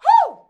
HUH 2.wav